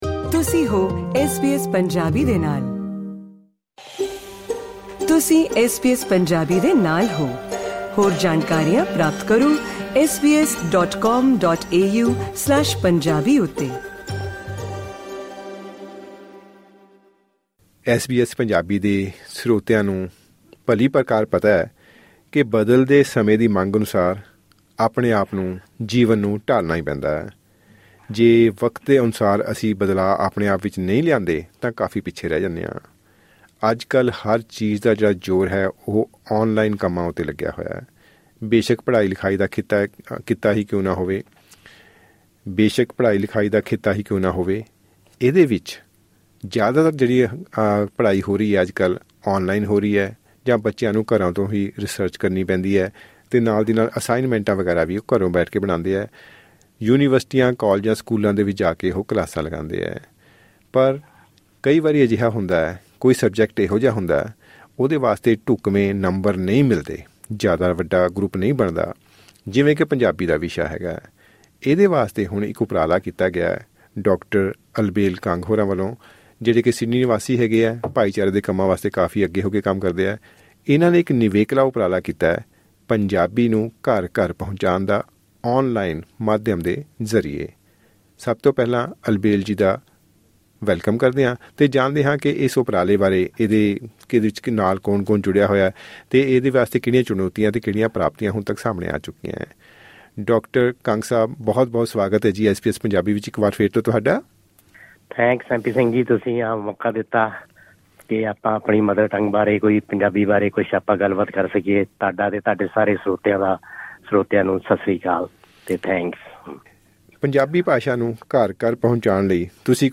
ਇਹ ਵਿਸ਼ੇਸ਼ ਗੱਲਬਾਤ...